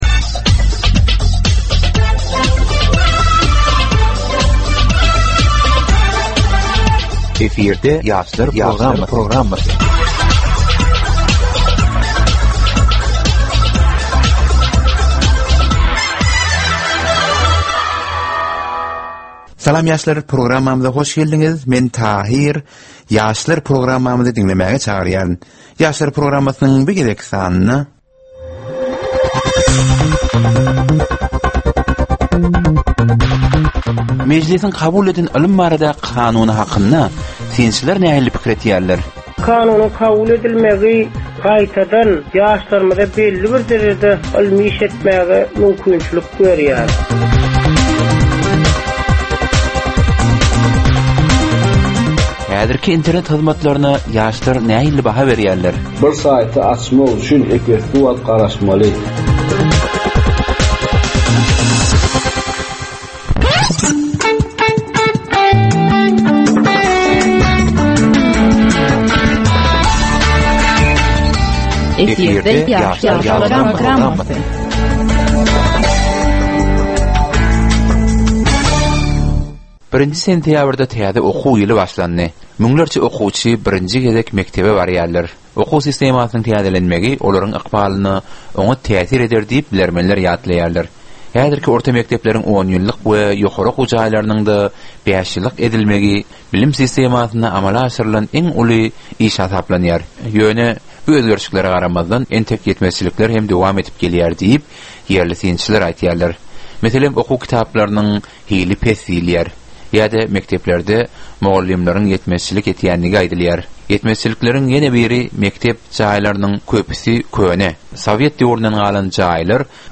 Türkmen we halkara yaslarynyn durmusyna degisli derwaýys meselelere we täzeliklere bagyslanylyp taýýarlanylýan 15 minutlyk ýörite geplesik.
Geplesigin dowmynda aýdym-sazlar hem esitdirilýär.